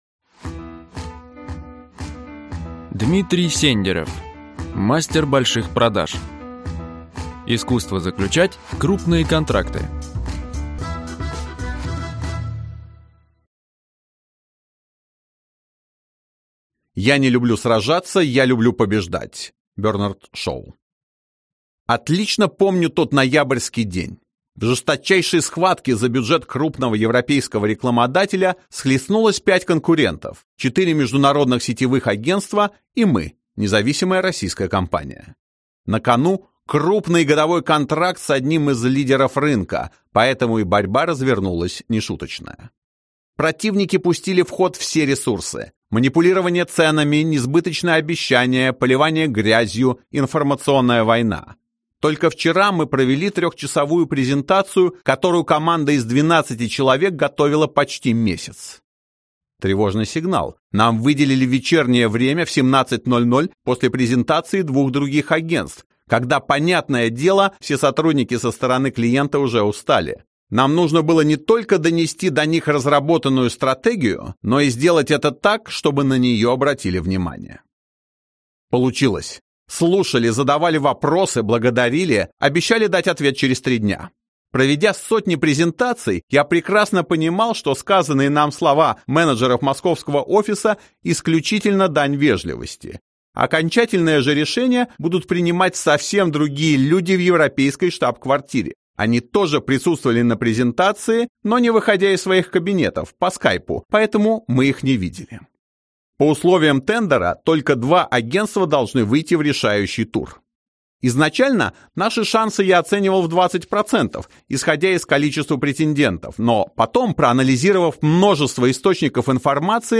Аудиокнига Мастер больших продаж | Библиотека аудиокниг